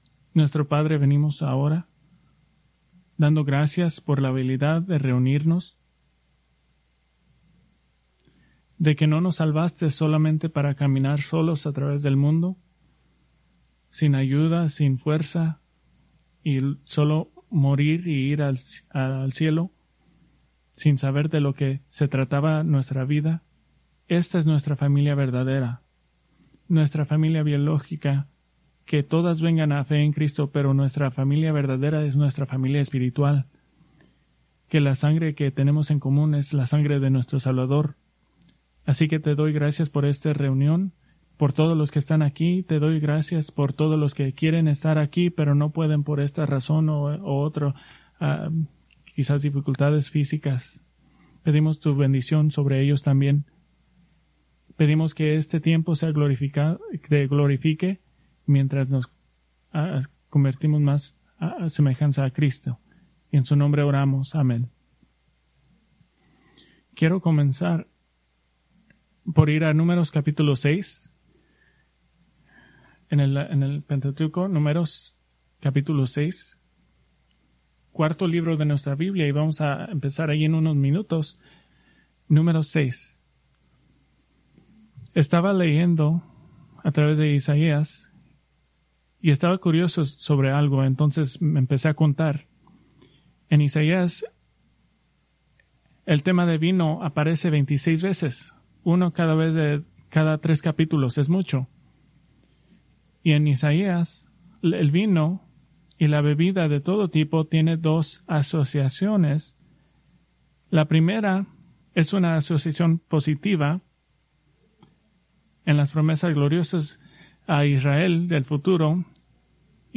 Preached September 21, 2025 from Escrituras seleccionadas